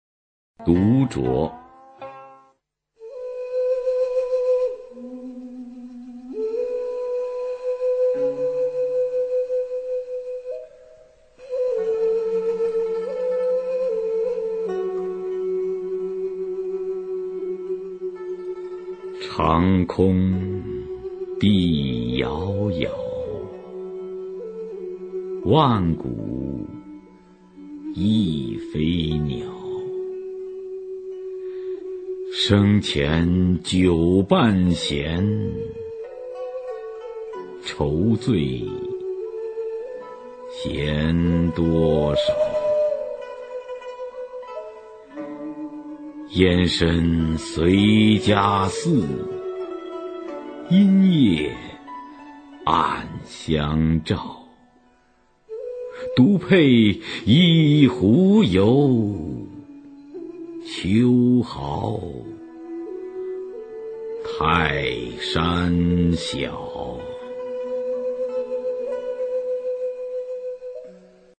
[隋唐诗词诵读]杜牧-独酌（长空） 古诗文诵读